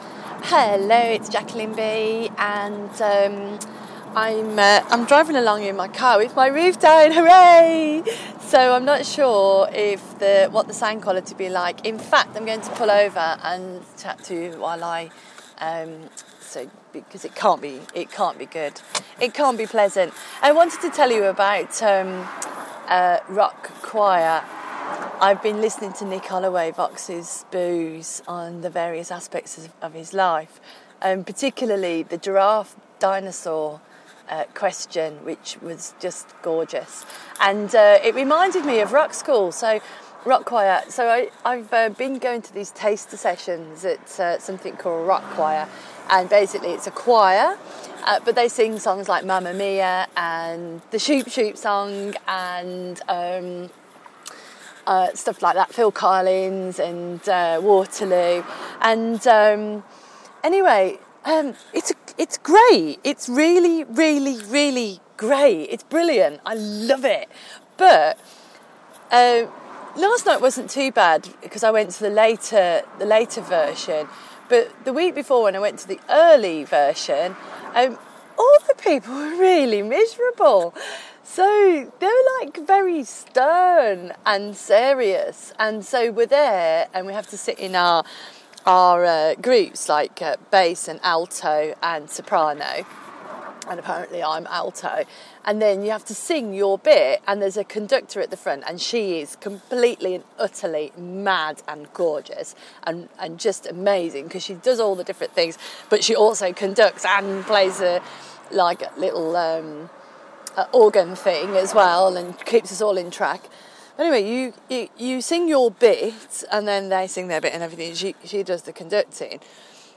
Rock choir